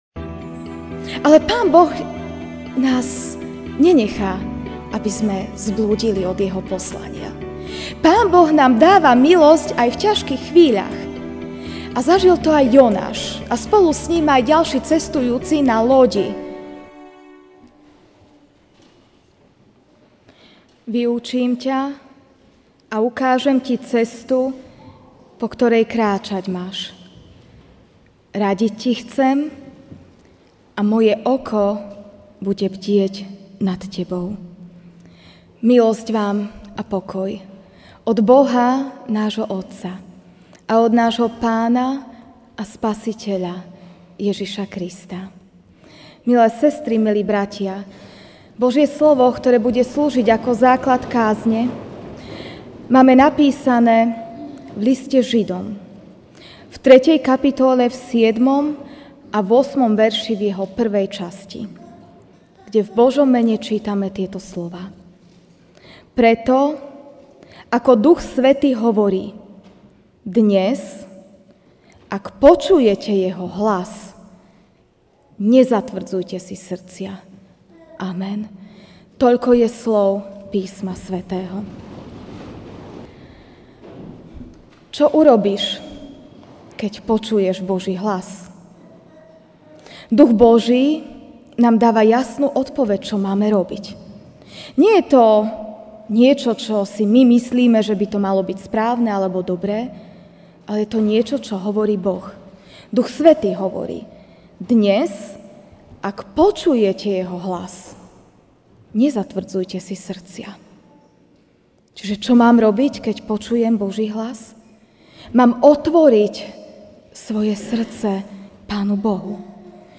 Ranná kázeň: Čo urobíš, keď počuješ Boží hlas? ( Židom 3, 7-8a.)Preto, ako Duch Svätý hovorí: Dnes, ak počujete jeho hlas, nezatvrdzujte si srdcia.